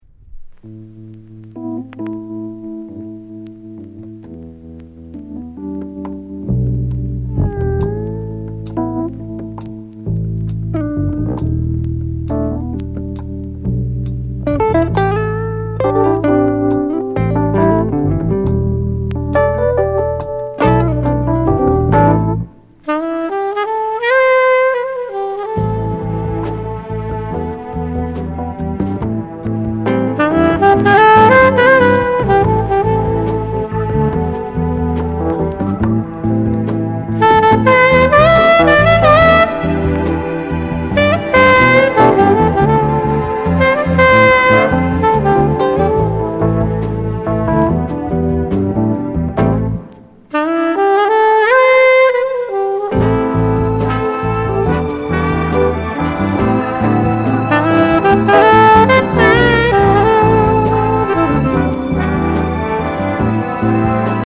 HORN INST